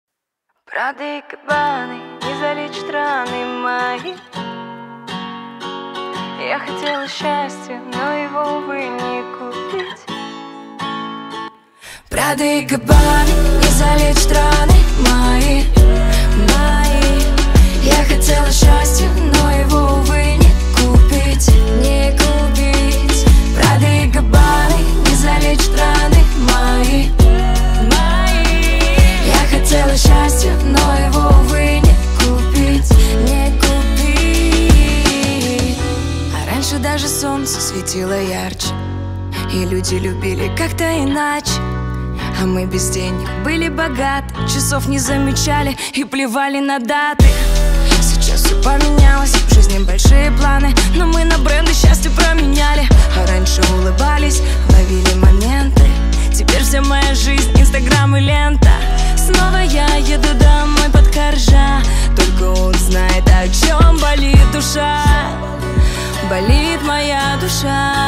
• Качество: 320, Stereo
поп
гитара
рэп
душевные